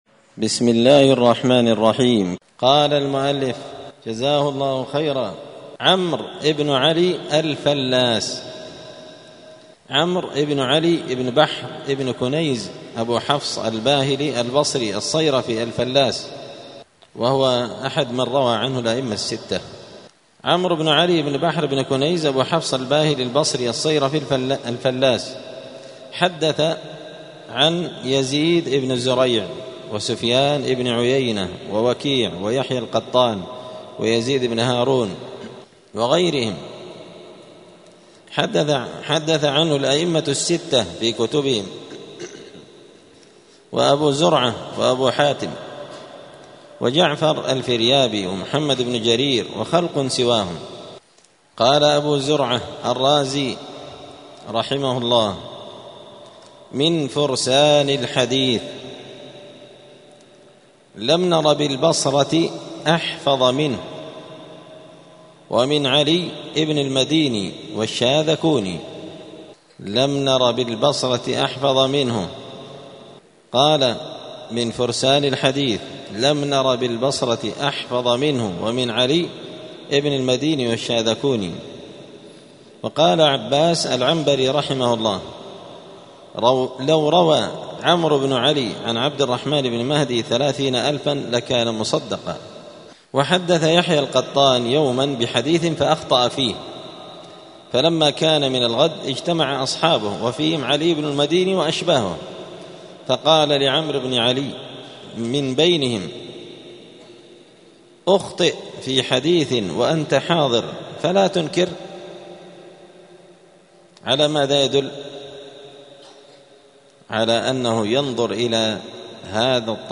*الدرس الخامس والتسعون (95) باب التعريف بالنقاد عمرو بن علي الفلاس*